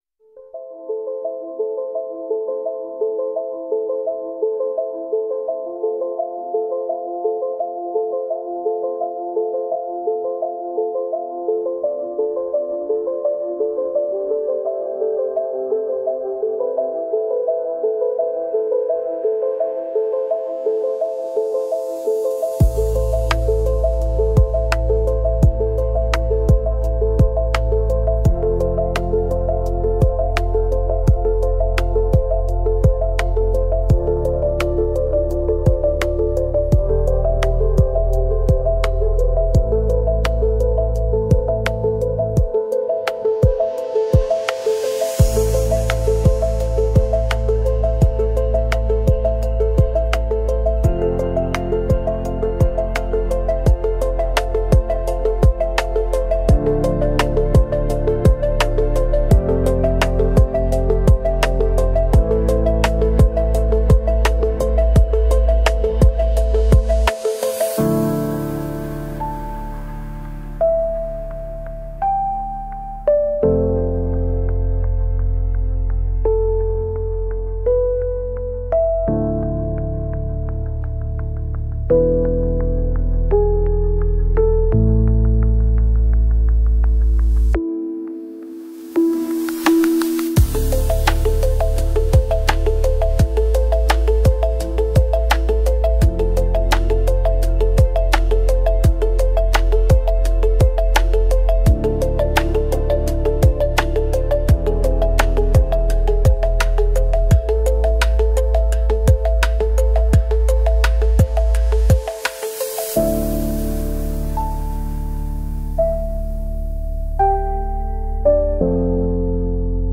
a music for games